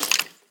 Minecraft Version Minecraft Version 1.21.4 Latest Release | Latest Snapshot 1.21.4 / assets / minecraft / sounds / mob / skeleton / step3.ogg Compare With Compare With Latest Release | Latest Snapshot